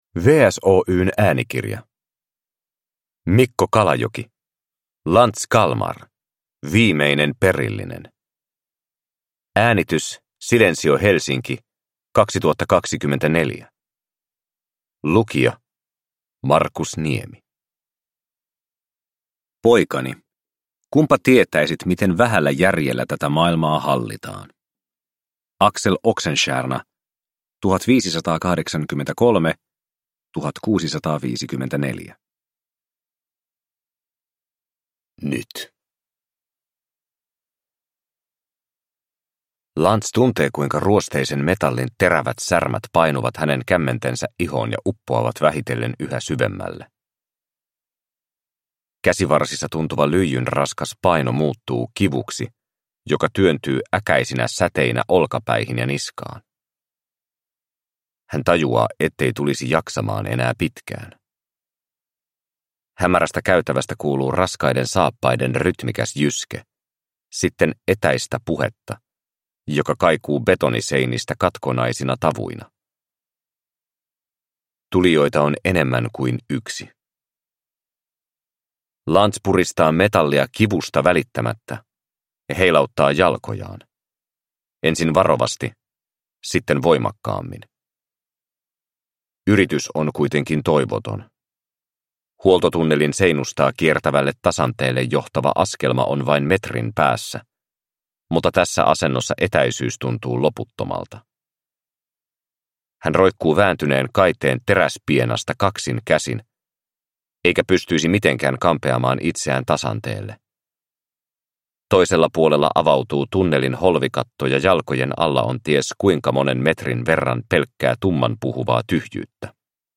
Lanz Kalmar - Viimeinen perillinen – Ljudbok